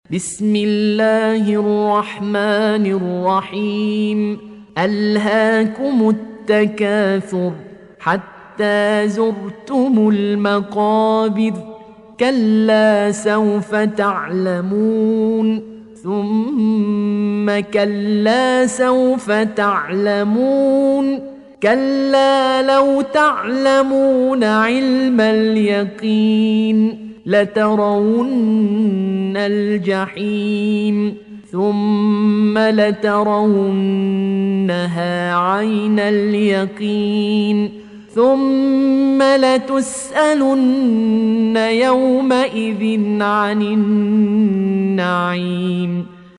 Surah Sequence تتابع السورة Download Surah حمّل السورة Reciting Murattalah Audio for 102. Surah At-Tak�thur سورة التكاثر N.B *Surah Includes Al-Basmalah Reciters Sequents تتابع التلاوات Reciters Repeats تكرار التلاوات